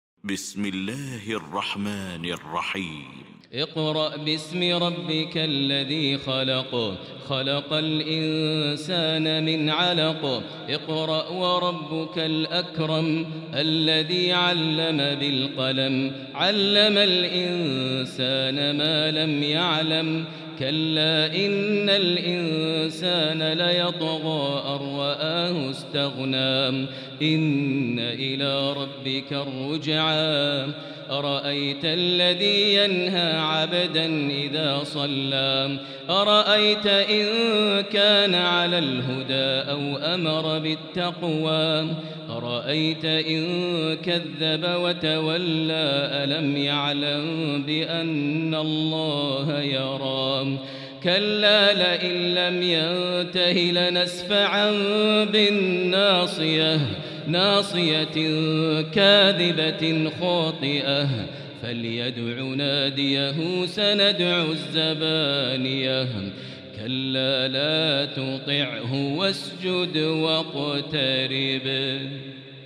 المكان: المسجد الحرام الشيخ: فضيلة الشيخ ماهر المعيقلي فضيلة الشيخ ماهر المعيقلي العلق